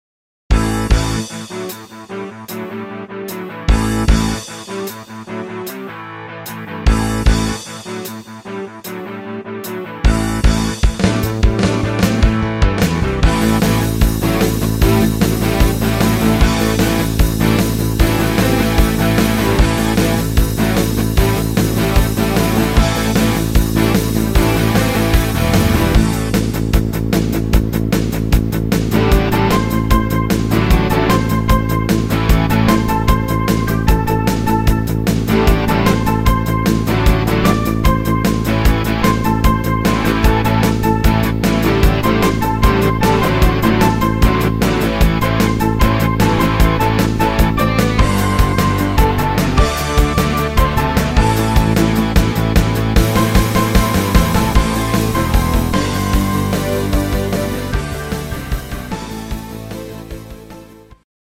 fetziger Rocksong